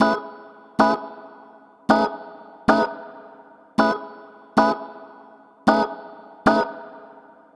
Swingerz 5 Organ-F#.wav